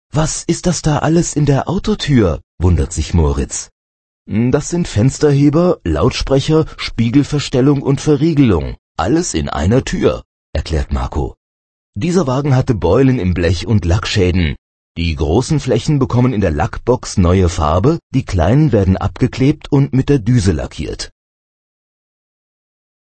Hörbuch Seite 8